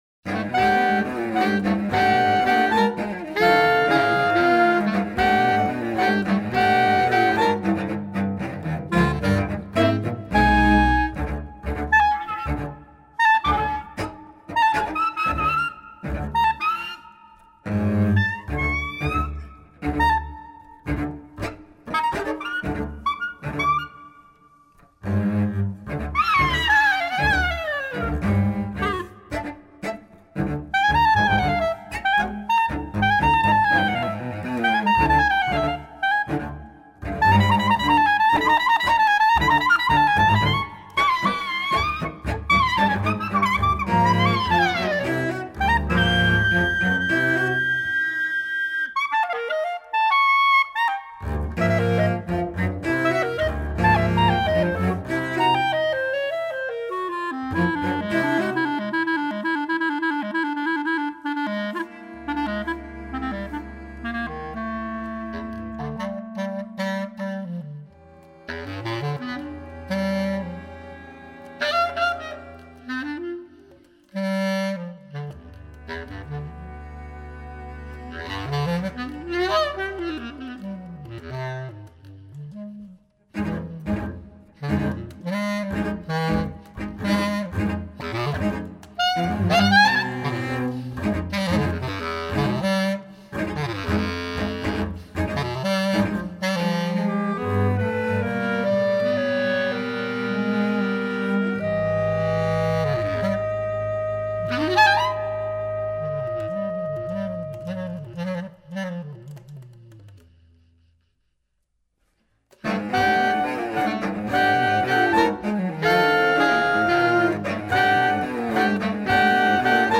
Stop by and hear the acoustic fireworks.